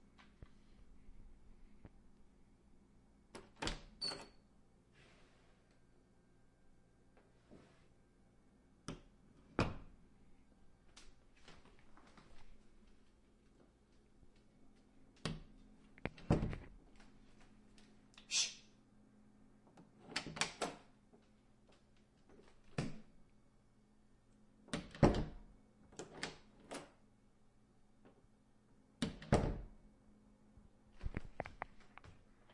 冰箱打开
描述：OWI开门关门橡胶